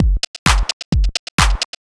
Dickie Beat_130.wav